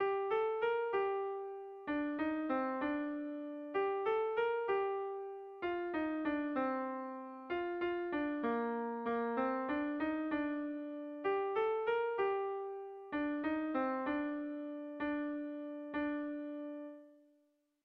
A1A2BA1